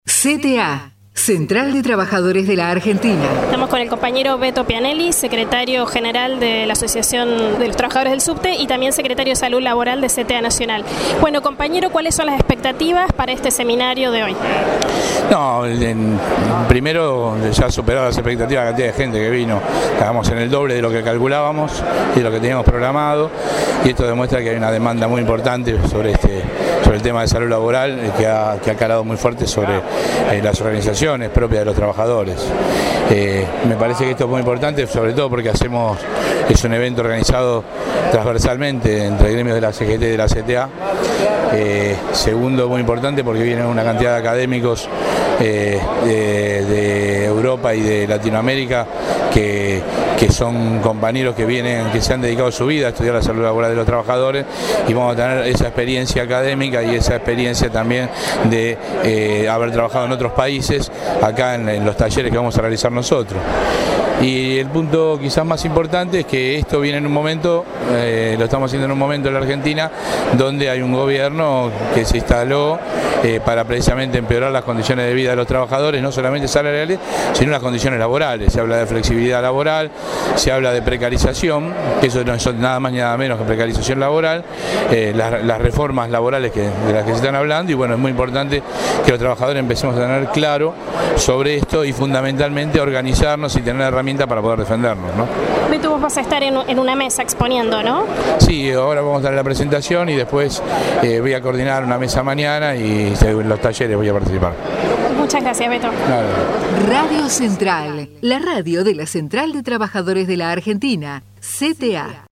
SEMINARIO INTERNACIONAL SALUD LABORAL